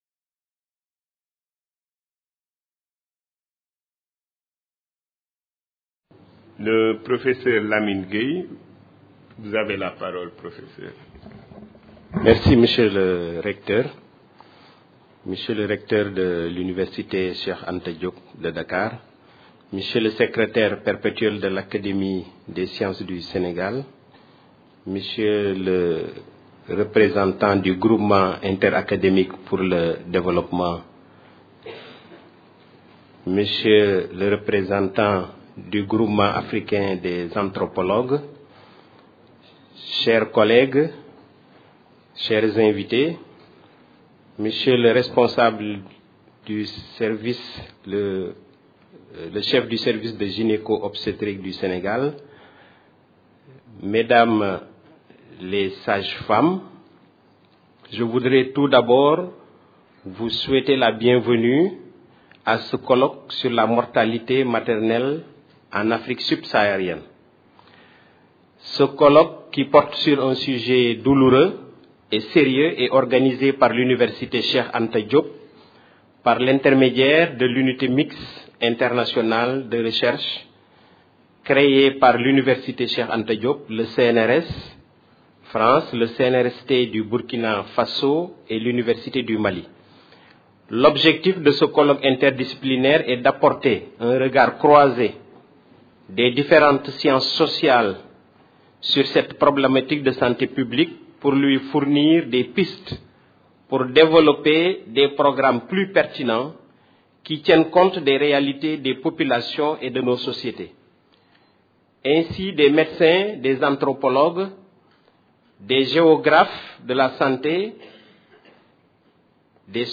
Réduction de la mortalité maternelle – Dakar 2010 - Introduction au colloque. Conférence enregistrée dans le cadre du Colloque International Interdisciplinaire : Droit et Santé en Afrique.
Session d'ouverture de la cérémonie.